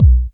909_KIK.WAV